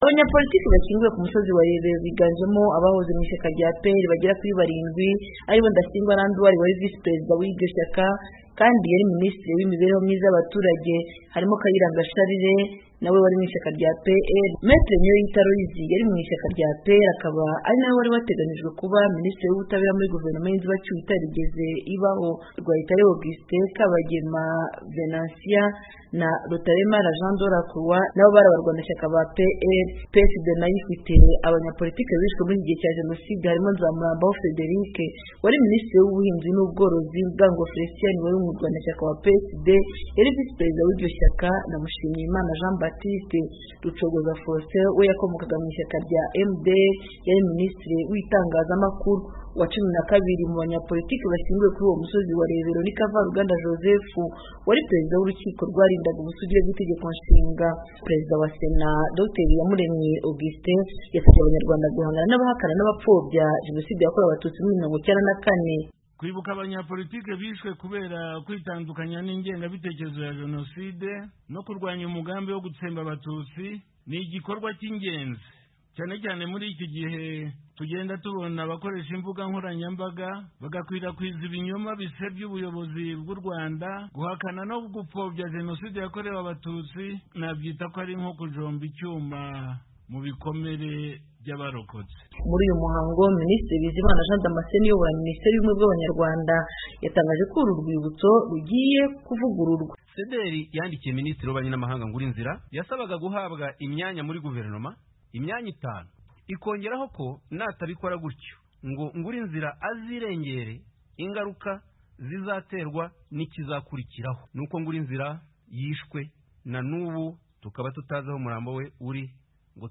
Senateri Iyamuremye Augustin wari umushyitsi mukuru muri uwo muhango
Uwo muhango wabereye ku musozi wa Rebero, ahashinguwe abanyapolitike 12 bishwe mu gihe cya jenoside yabaye mu Rwanda mu 1994.